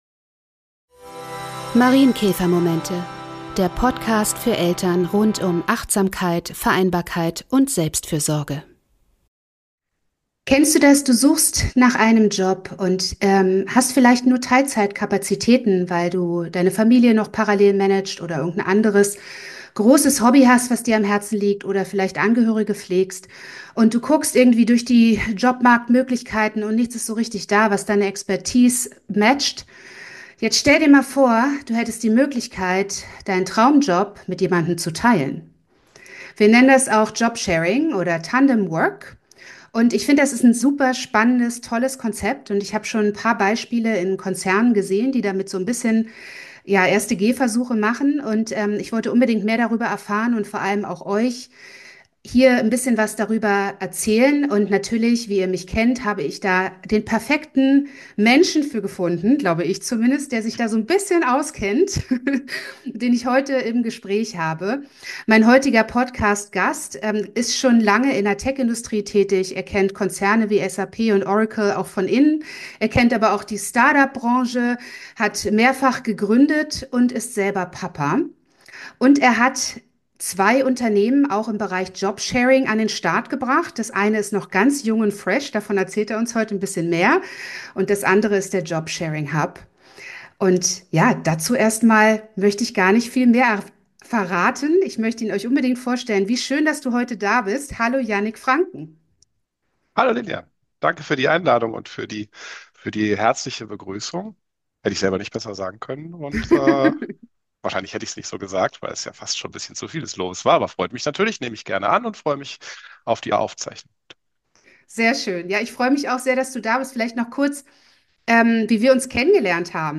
Ein inspirierendes und impulsreiches Interview für alle, die sich schon mal gefragt haben, wie Job Sharing und Tandemarbeit wirklich funktionieren kann.